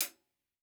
TC Live HiHat 11.wav